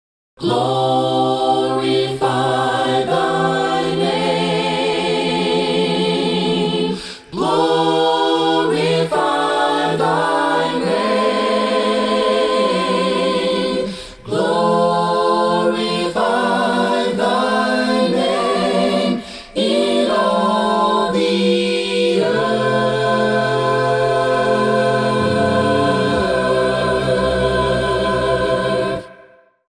Your source for the best in A'cappella Christian Vusic ®